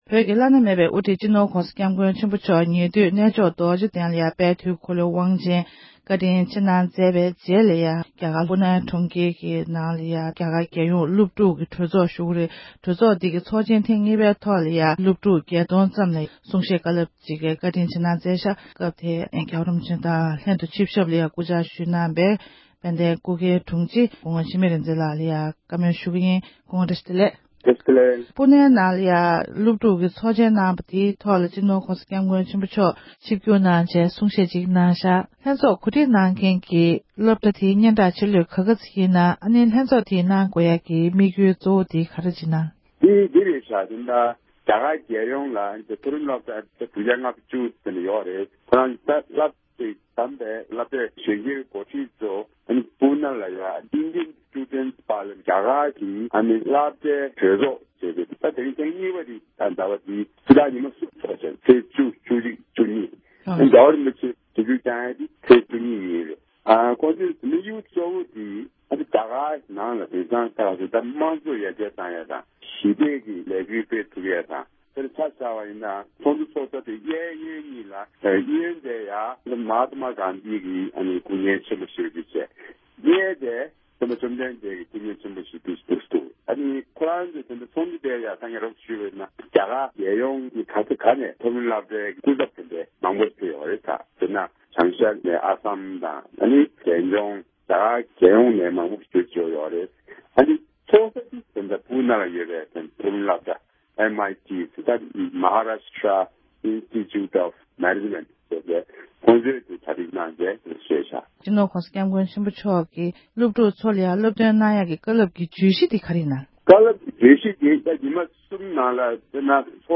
སྒྲ་ལྡན་གསར་འགྱུར།
བཀའ་འདྲི་ཞུས་པ་ཞིག